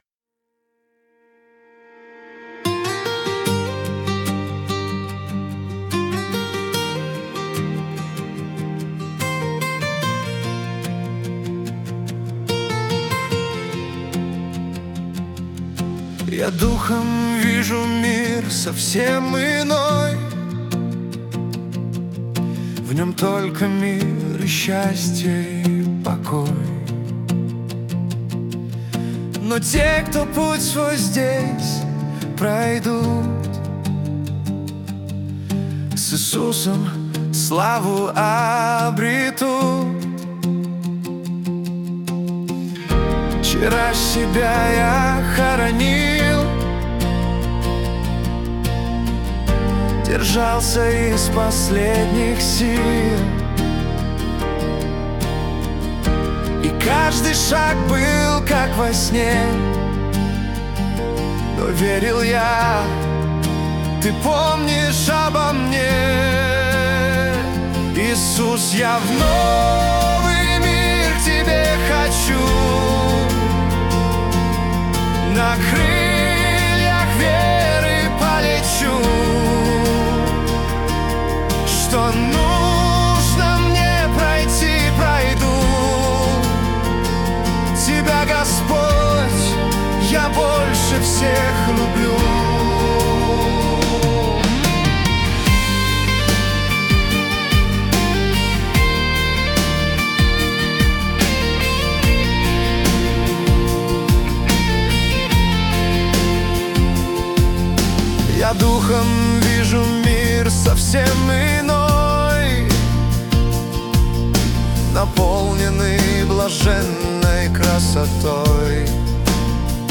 песня ai
177 просмотров 695 прослушиваний 75 скачиваний BPM: 73